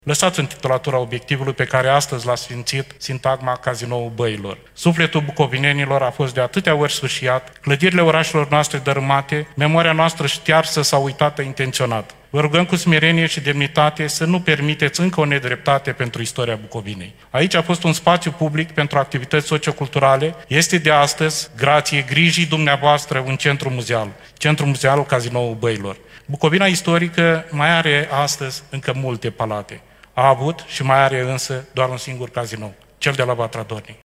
Solicitarea a fost formulată ieri, cu prilejul redeschiderii acestui obiectiv, refăcut și modernizat cu fonduri europene.